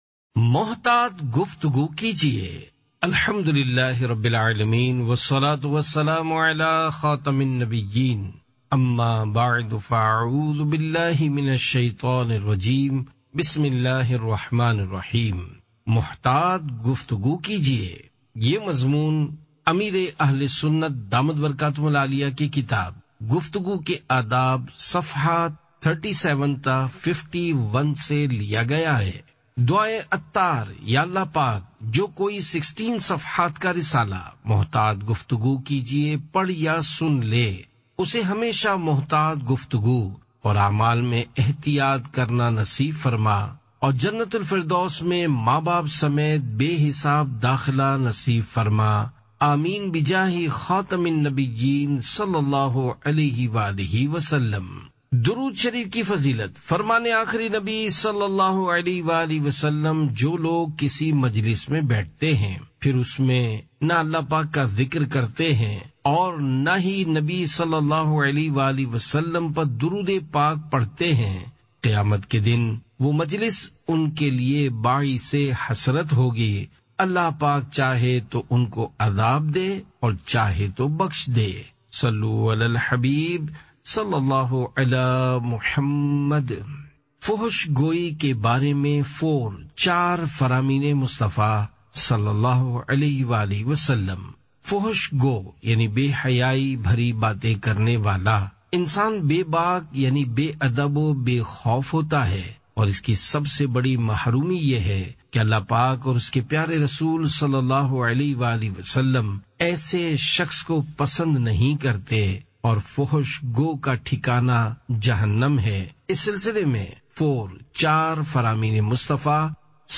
Audiobook - Muhtat Guftagu Kijiye (Urdu)